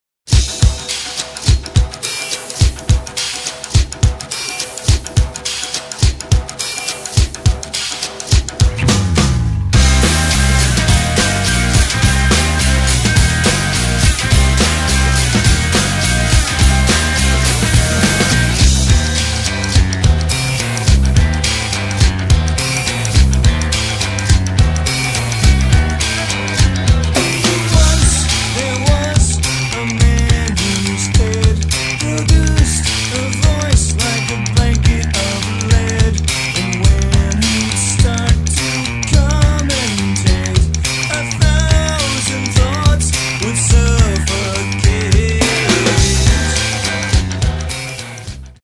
cleverly crafted pop songs